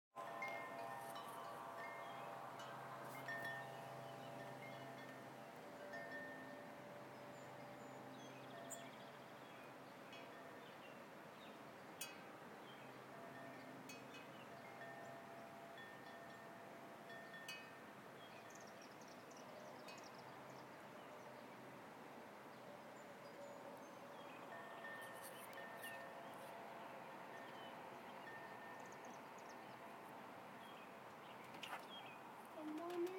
summer sounds, windchimes, birds